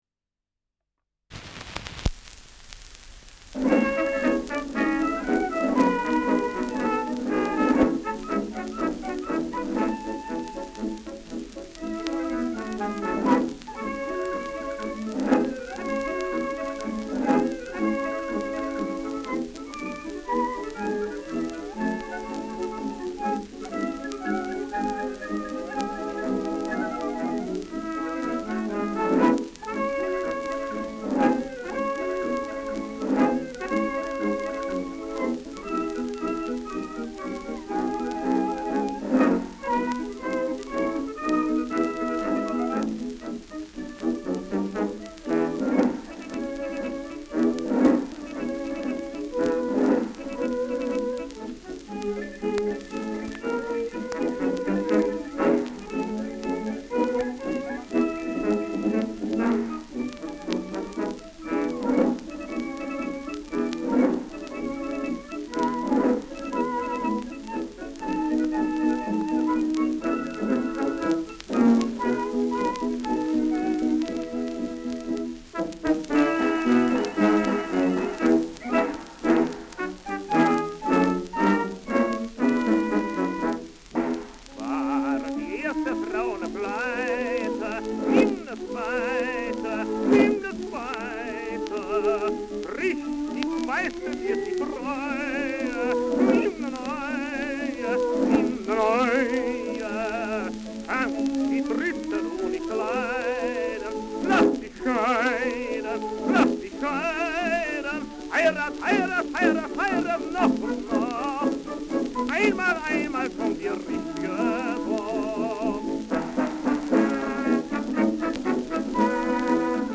Schellackplatte
Leicht abgespielt : Durchgehend leichtes Knacken
mit Gesang